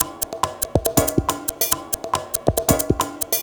Percussion 07.wav